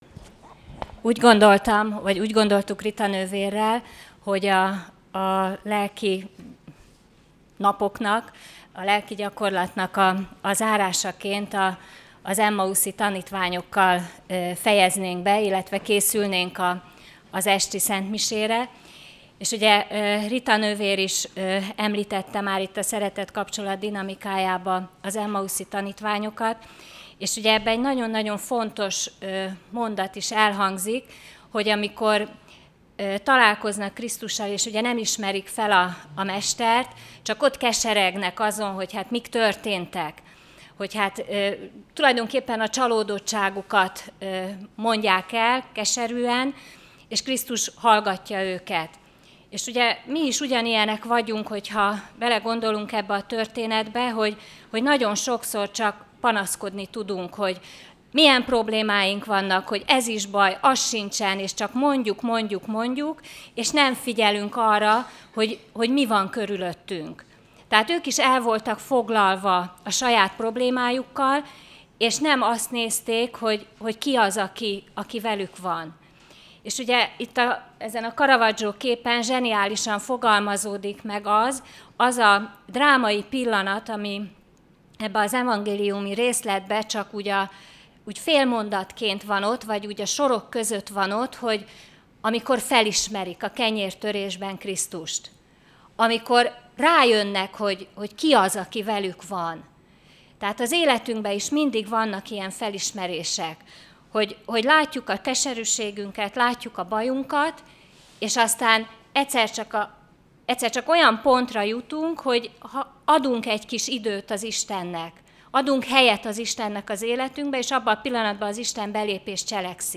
Az alábbi hanganyagok a lelkigyakorlaton elhangzott előadásait tartalmazzák, melyek a katekéta identitás elmélyítését szolgálták.